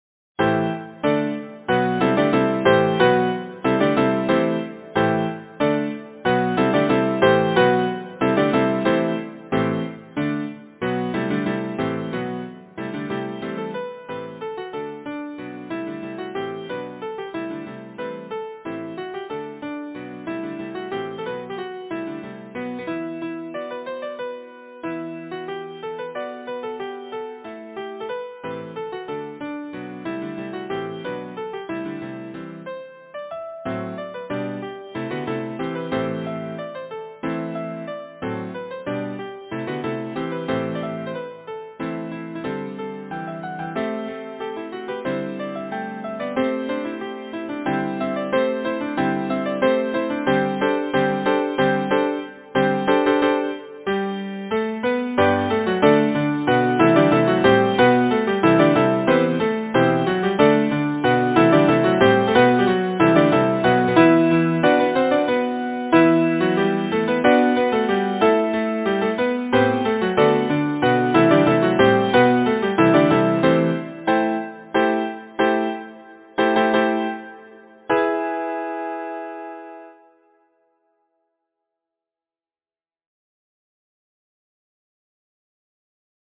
Number of voices: 4vv Voicing: SATB, with divisi Genre: Secular, Folksong
Language: English Instruments: A cappella